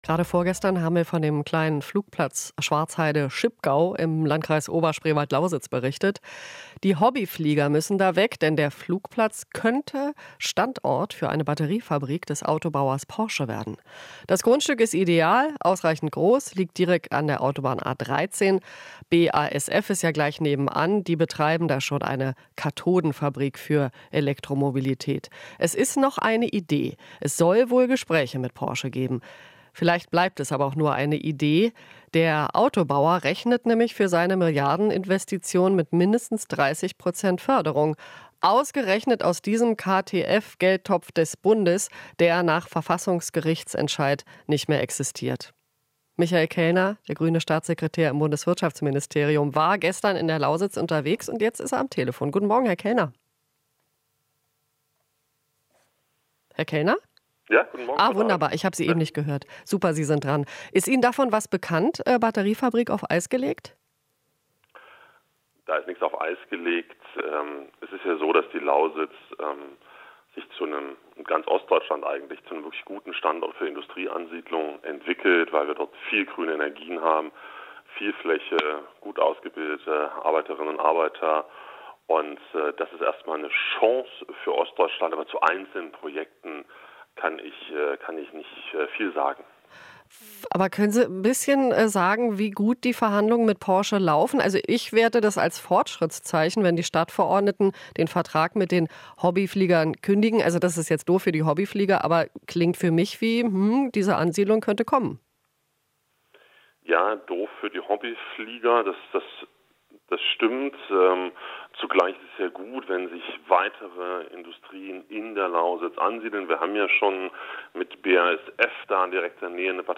Interview - Staatssekretär Kellner: Ostdeutsche Förderzusagen jetzt nicht möglich